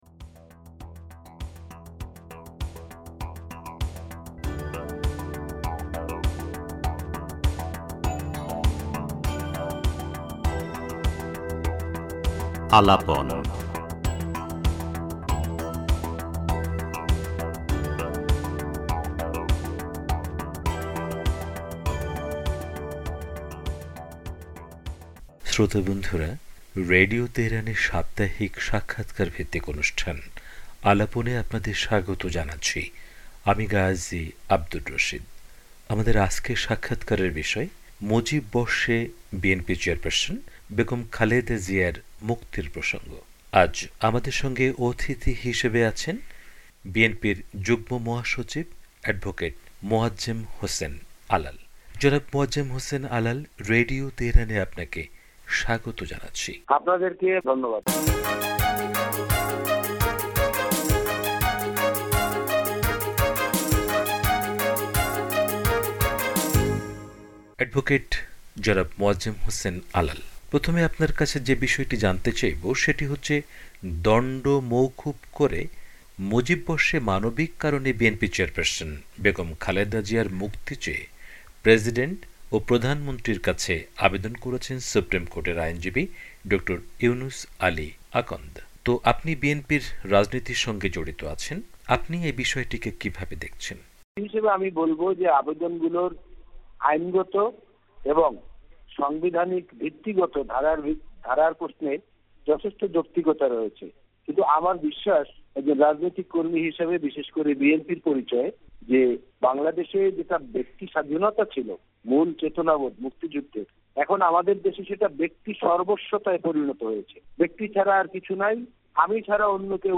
তিনি বলেন,খালেদা জিয়ার মুক্তির ব্যাপারে আইনি প্রক্রিয়াগুলোর যৌক্তিক ও সাংবিধানিক ভিত্তি থাকলেও বর্তমান ব্যক্তি সর্বস্ব পরিস্থিতিতে কিছু হবে বলে তিনি আশা করেন না। সাক্ষাৎকারটি তুলে ধরা হলো।